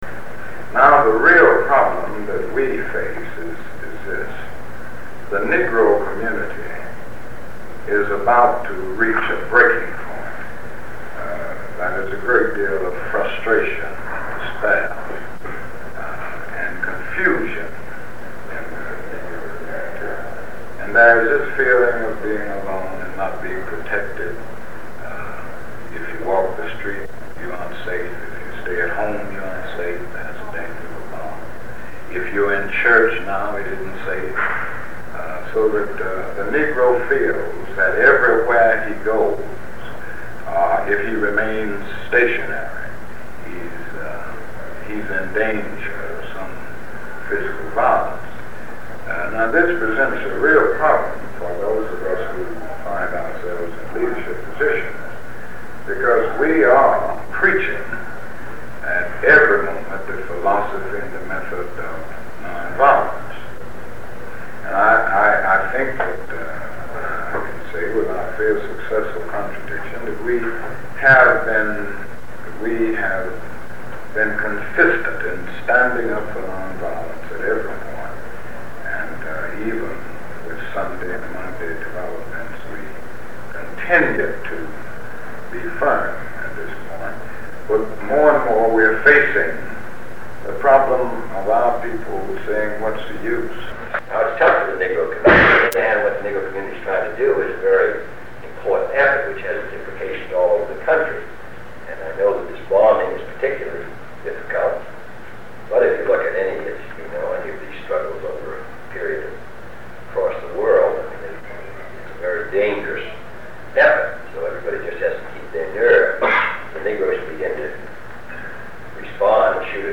President Kennedy and Martin Luther King, Jr., 19 Sept. 1963, in a meeting with other civil rights leaders, discussing the aftereffects of the Birmingham church bombing that killed four African-American children